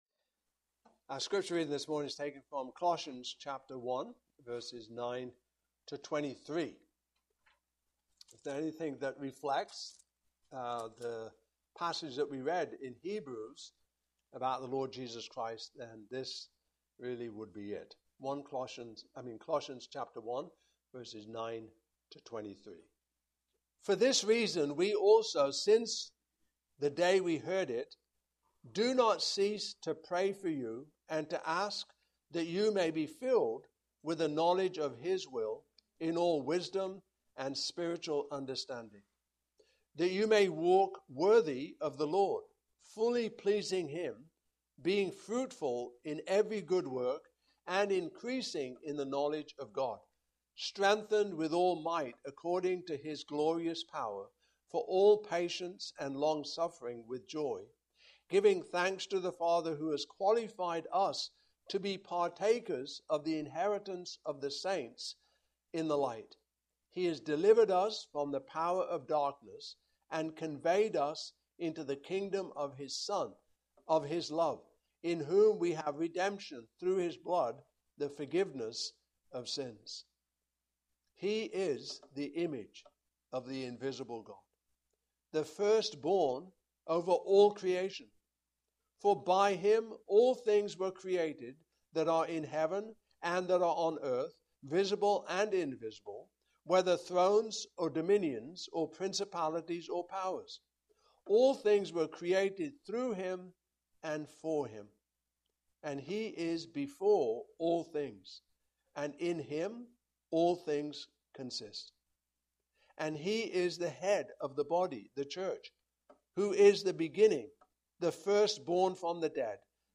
Passage: Colossians 1:9-23 Service Type: Morning Service